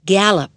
GALLOP.mp3